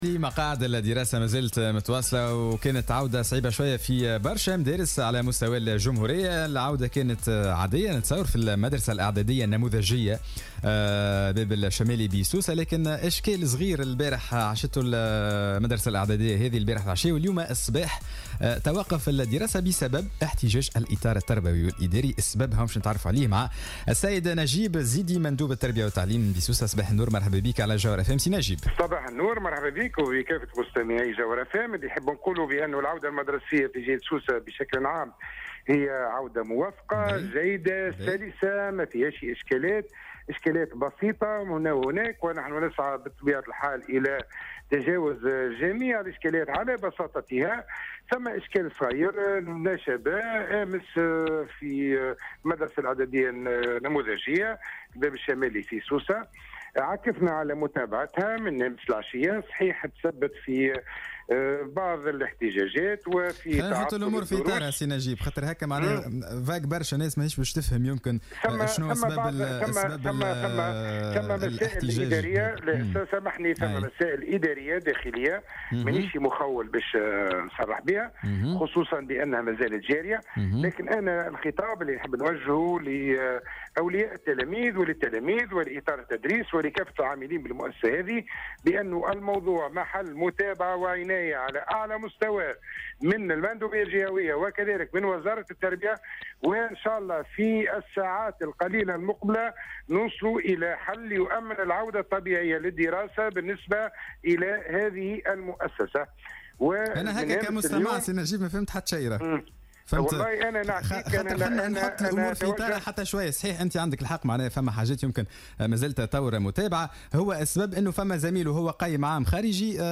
أكد المندوب الجهوي للتربية بسوسة نجيب الزيدي في تصريح للجوهرة "اف ام" اليوم الثلاثاء أن العودة المدرسية في سوسة كانت جيدة وسلسة وموفقة لم تشهد إلا اشكالات بسيطة يتم النظر في كيفية حلها على حد قوله.